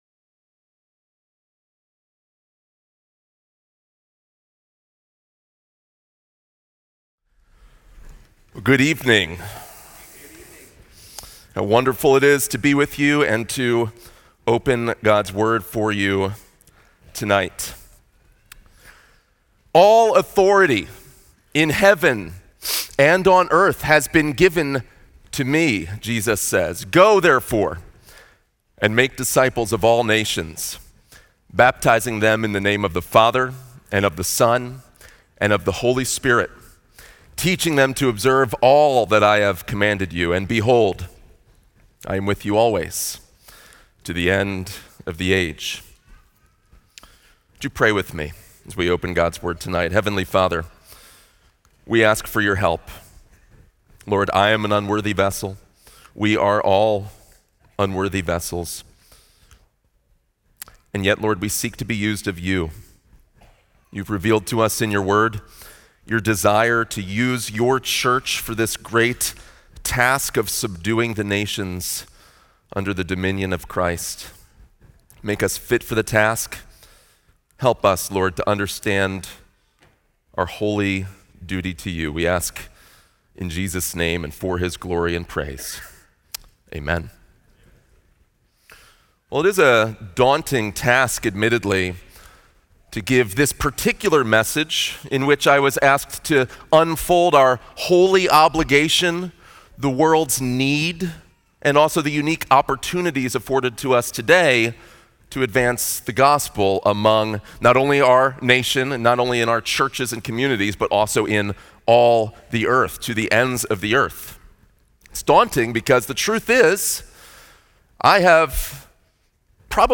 Sermons by Founders Ministries God's Mandate to the Ends of the Earth